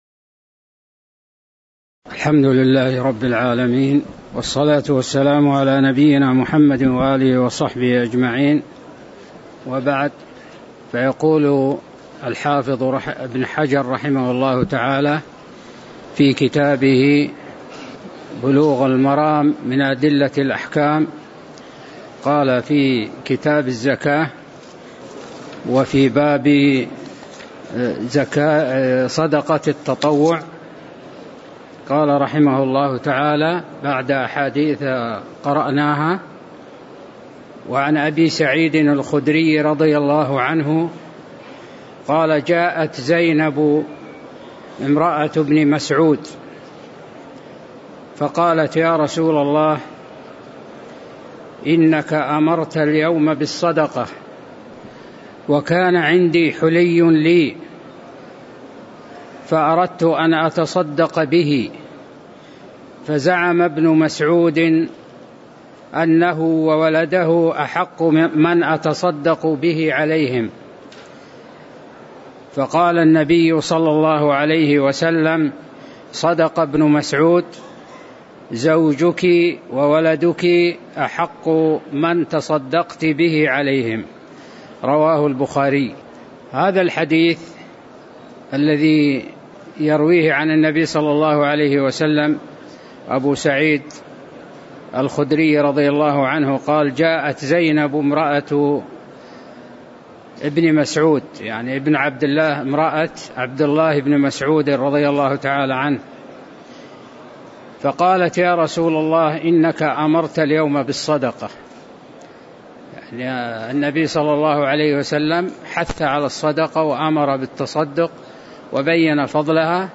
تاريخ النشر ٨ صفر ١٤٤٠ هـ المكان: المسجد النبوي الشيخ